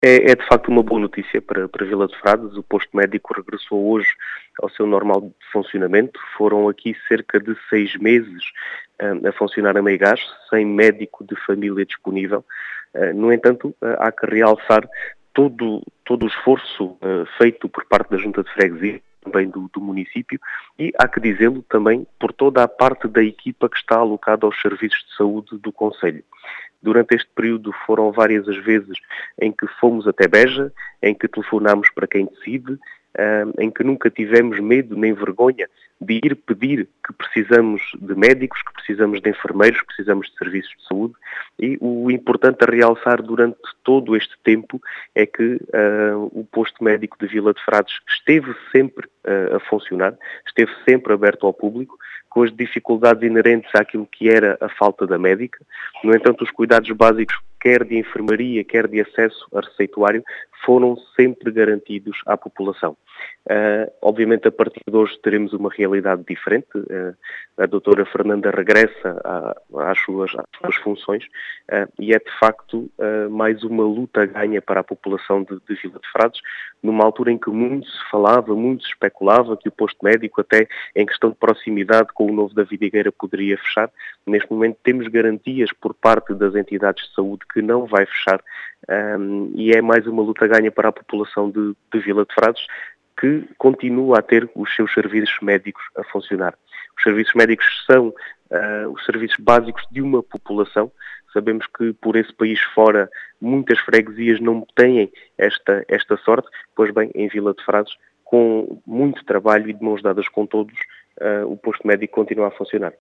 As explicações são de Diogo Conqueiro, presidente da junta de freguesia de Vila de Frades, que diz tratar-se de uma “boa noticia”, uma vez que a saúde é um “serviço básico” para a população.